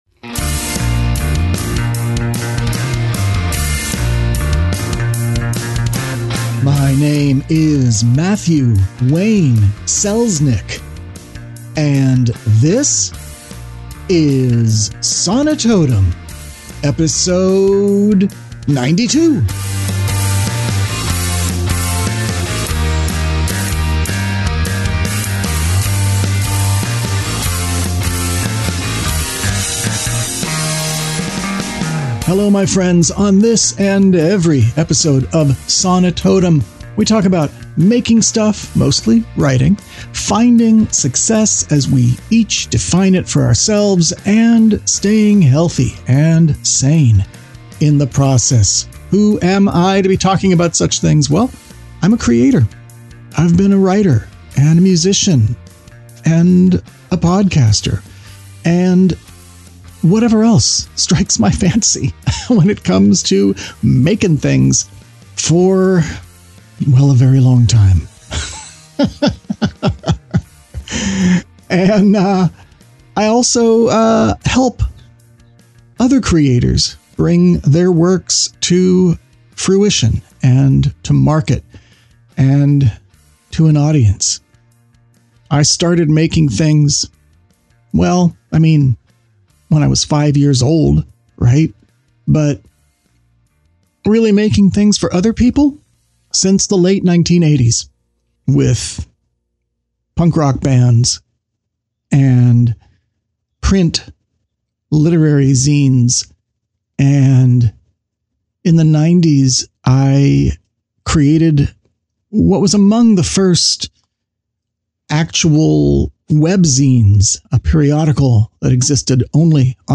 Sonitotum 092 is a solo episode about introversion, solitude, recharging your creative energies, and finding your way back to your creative home and hearth.